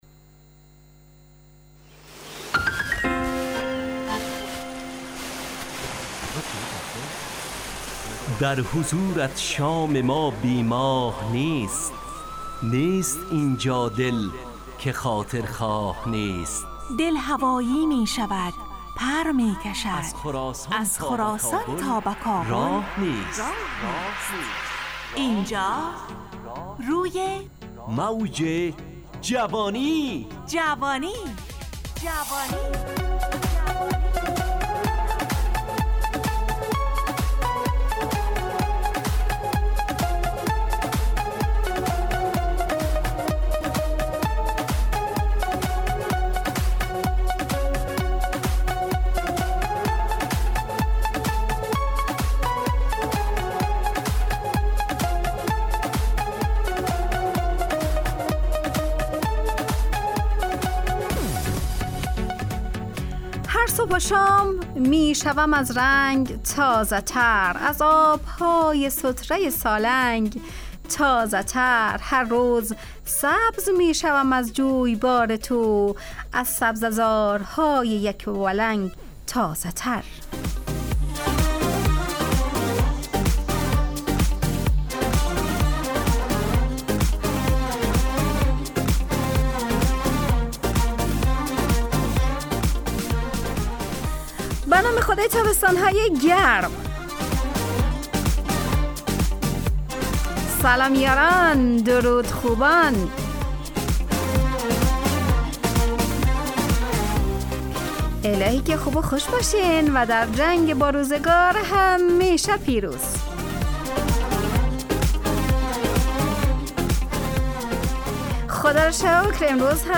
همراه با ترانه و موسیقی مدت برنامه 70 دقیقه . بحث محوری این هفته (کار و کمایی)